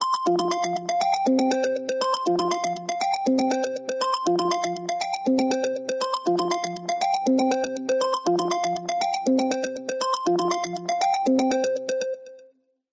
Darmowe dzwonki - kategoria SMS
Ciekawy dźwięk sugerujący czekanie na odbiór połączenia.